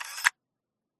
Free UI/UX sound effect: Button Click.
Button Click
370_button_click.mp3